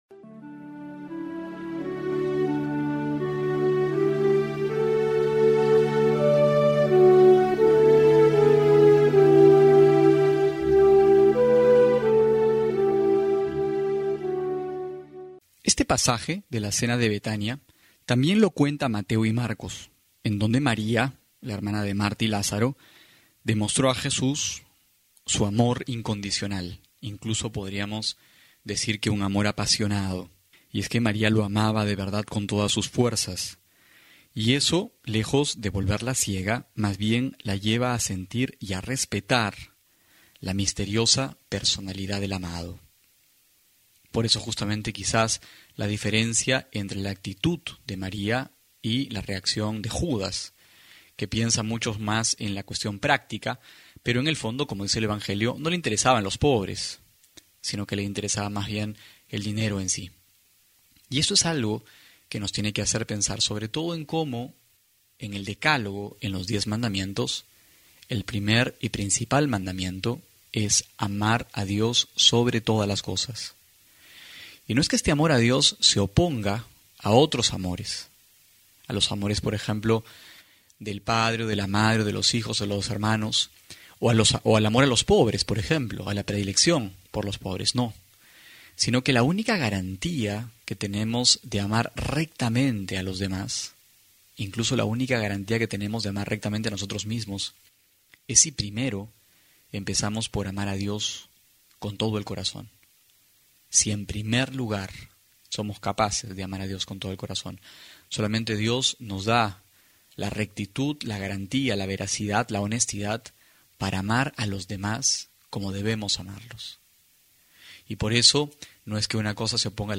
Homilía para hoy: Juan 12,1-11
abril02-12homilia.mp3